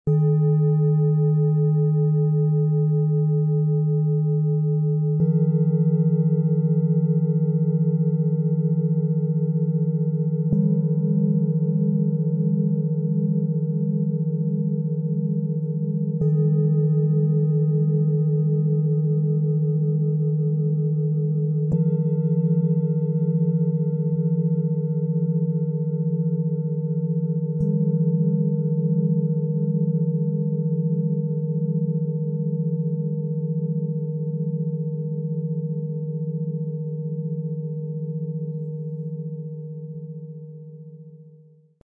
Ruhig, harmonisch und lichtvoll - Klangmassage Set für Kinder und Jugendlichen aus 3 Klangschalen, Ø 17,2 - 20,2 cm, 2,77 kg
Die tiefe Schale schenkt stabilisierende Erdung, beruhigt den Geist und gibt eine klare innere Struktur für den Alltag.
Der hohe, freundliche Ton schafft eine sanfte Verbindung zum eigenen Selbst und unterstützt ein wohlwollendes, gelassenes Selbstgefühl.
Dank unseres Sound-Player - Jetzt reinhörens können Sie den echten Klang dieser speziellen Schalen des Sets selbst anhören.
Bengalen Schale, Glänzend, 20,2 cm Durchmesser, 8,5 cm Höhe
MaterialBronze